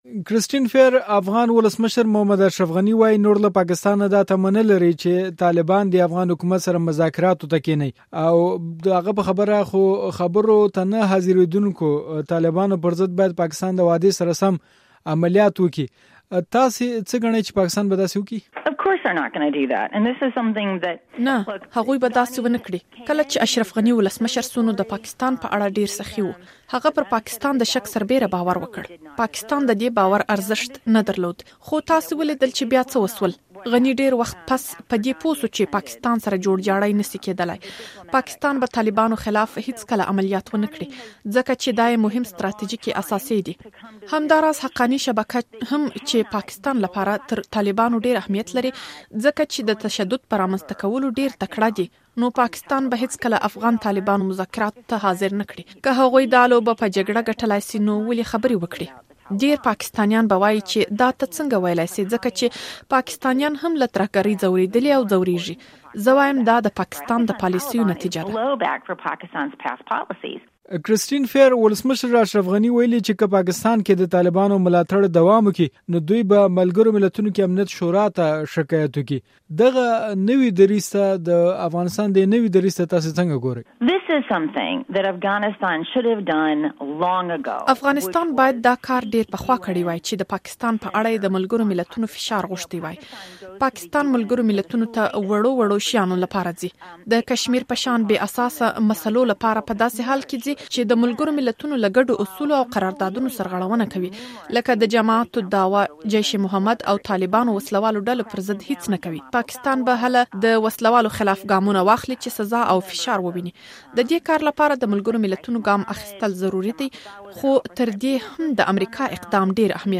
د افغانستان، طالبانو او پاکستان په اړه د کرسټين فيير سره مرکه